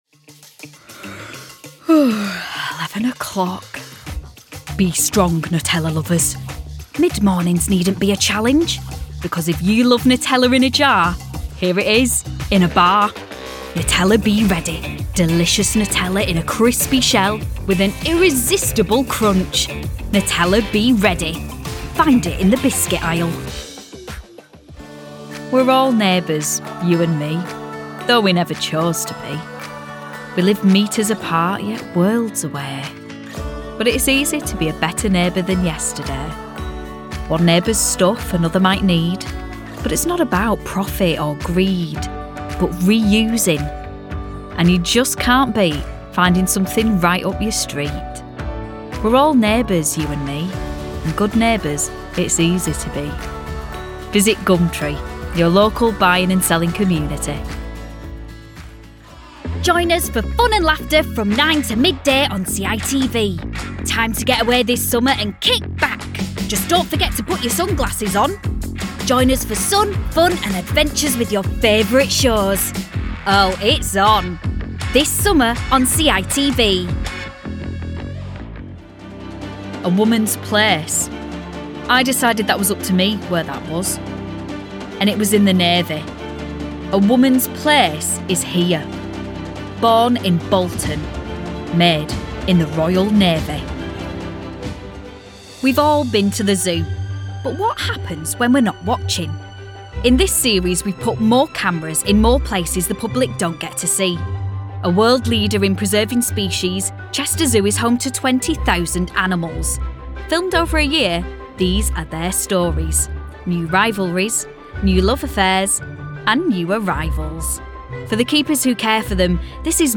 Male 30s , 40s , 50s , 60s , 60s + American English (Native) , Canadian English (Native) , French Canadian (Native) , British English (Native) , Latin American Spanish Approachable , Assured , Authoritative , Character , Confident , Corporate , Deep , Energetic , Engaging , Friendly , Gravitas , Reassuring , Versatile , Warm Character , Commercial , Corporate , Documentary , Educational , E-Learning , Explainer , IVR or Phone Messaging , Narration , Podcasts , Training , Video Game